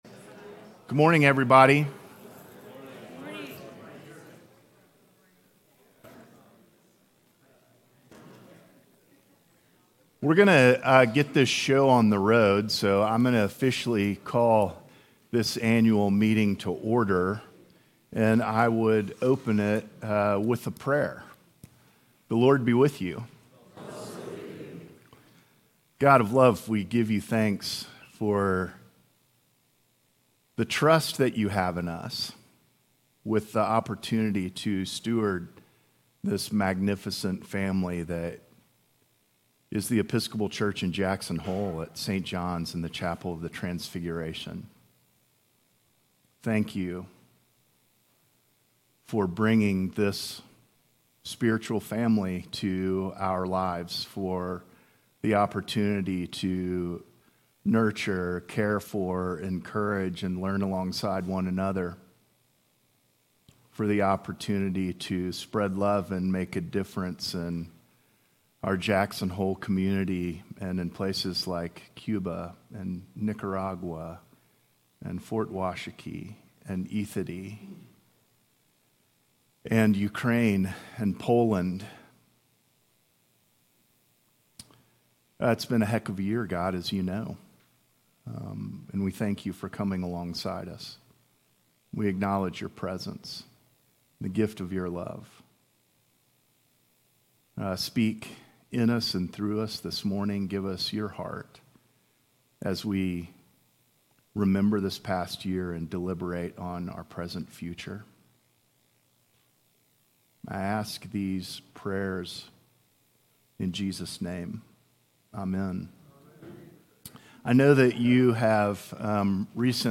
Annual Meeting Rector Report